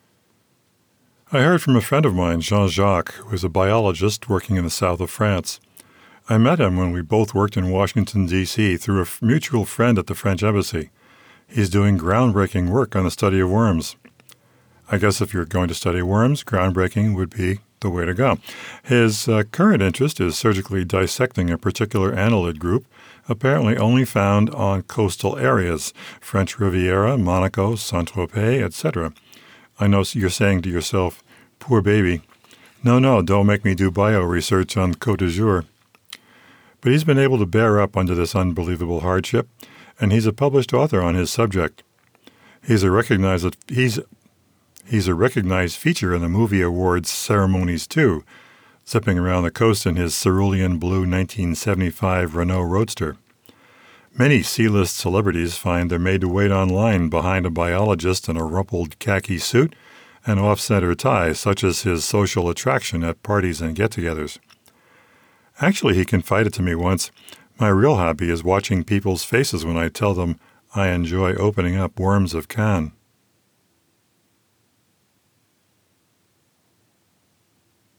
This was a raw voice test I shot. It passes ACX Audiobook conformance although nobody in their right mind would OK it for publication.